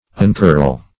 Uncurl \Un*curl"\, v. i.